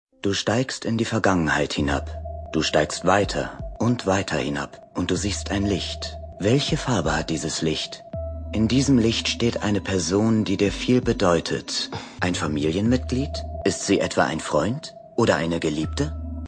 Audio Drama (Hörspiel)